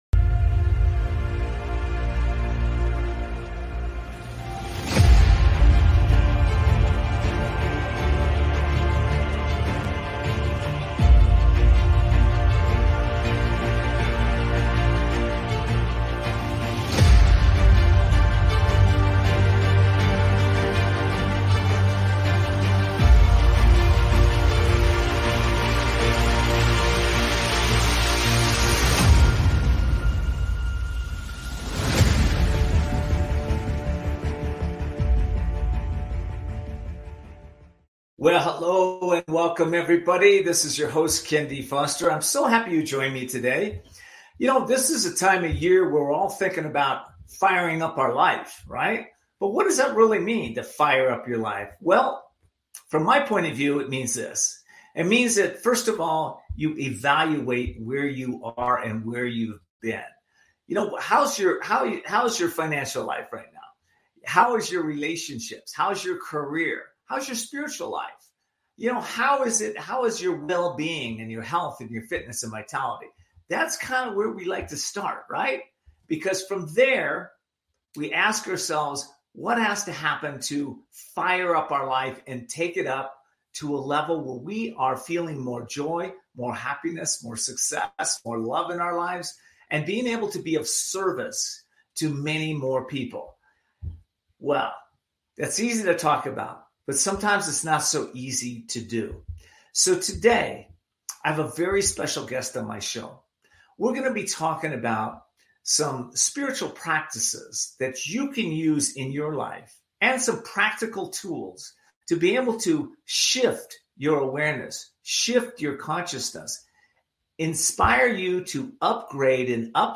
Guest, Dr Joe Vitale, spiritual teacher, actor in, The Secret, author of, The Attractor Factor